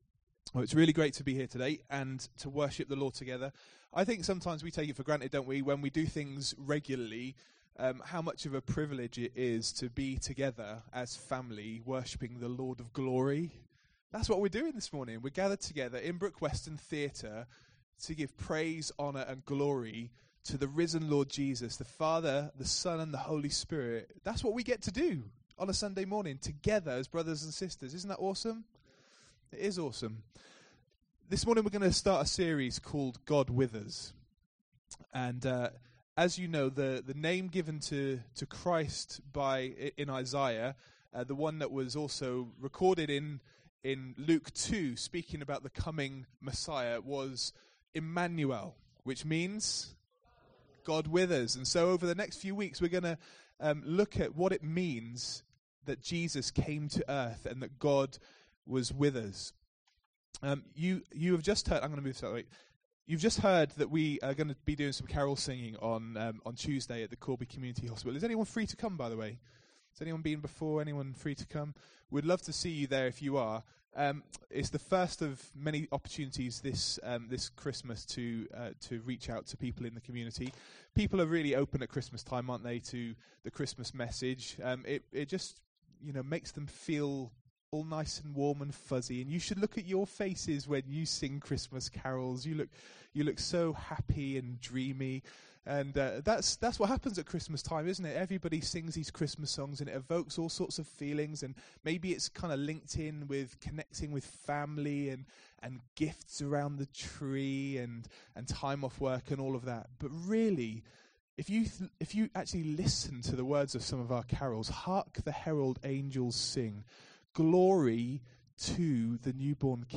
This first sermon focusses on John 1:14 and he unpacks what scripture means when it tells us "the Word became flesh."